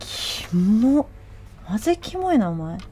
Worms speechbanks
Illgetyou.wav